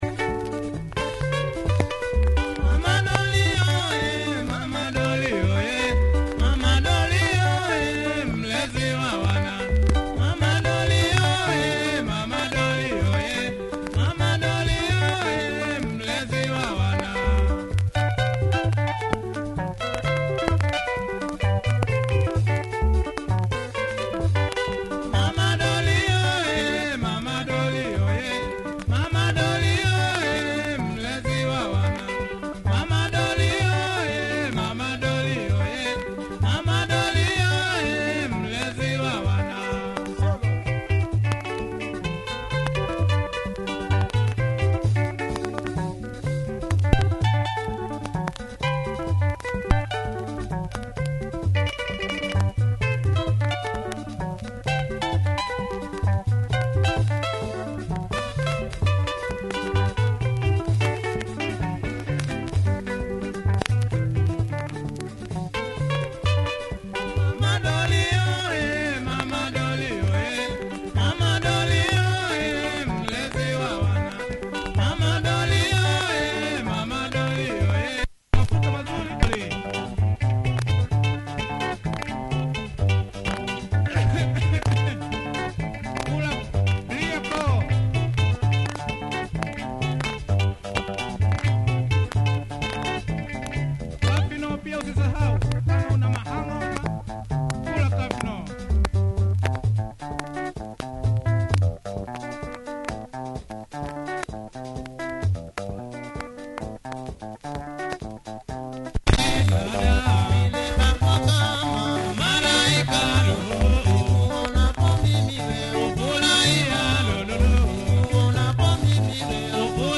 has some marks in the start that creates skips.